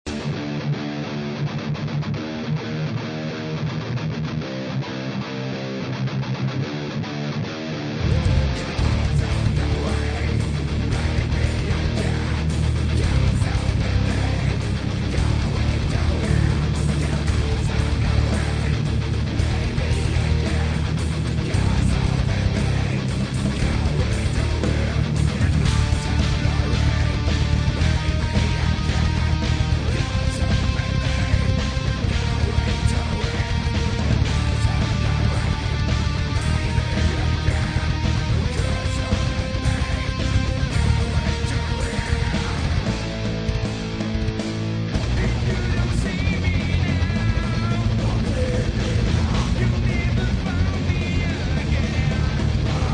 Pour du métal high gain, c'est tout ce qui faut, les crunchs bluezzys sont vraiment bons...